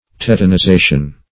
Search Result for " tetanization" : The Collaborative International Dictionary of English v.0.48: Tetanization \Tet`a*ni*za"tion\, n. (Physiol.) The production or condition of tetanus.
tetanization.mp3